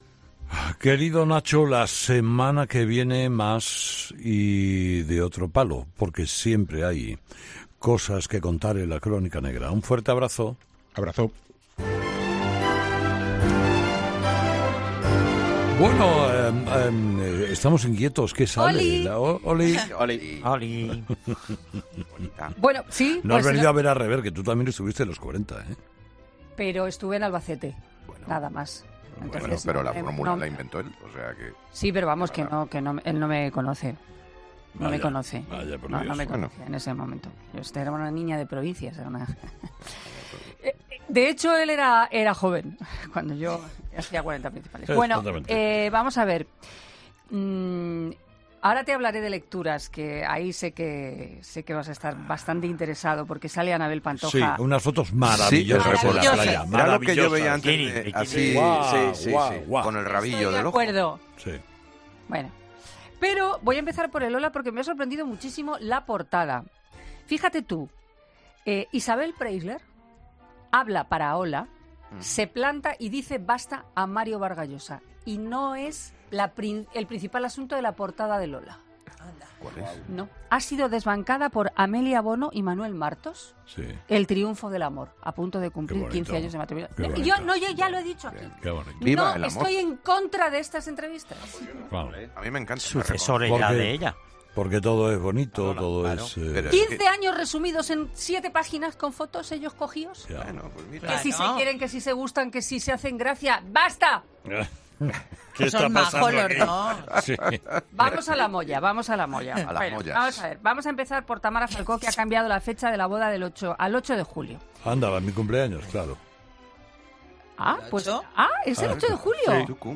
Carlos Herrera descubre en directo el dato personal que le conecta con Tamara Falcó: "Se te ha ido la lengua"
Carlos Herrera se ha sincerado durante las 'Revistas del corazón' de 'Herrera en COPE'